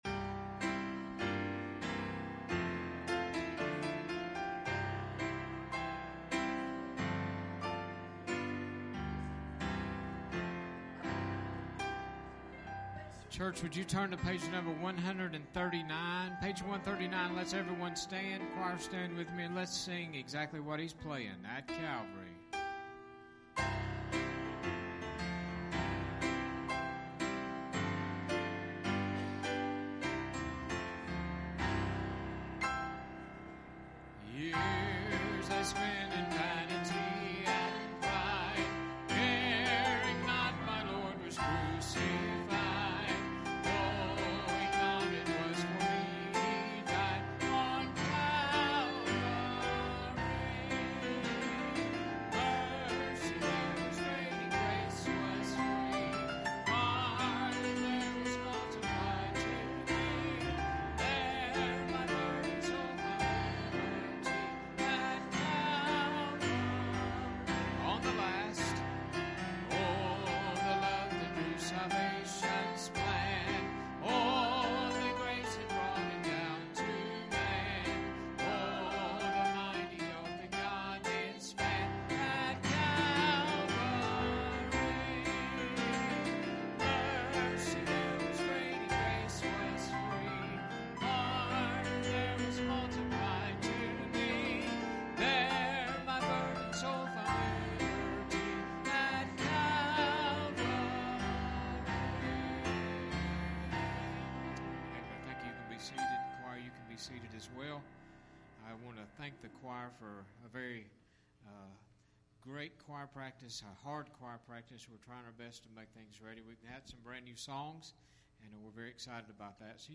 Prayer Meeting
Message Service Type: Sunday Evening « The Rise And Fall If King Uzziah The I Am Statements Of Jesus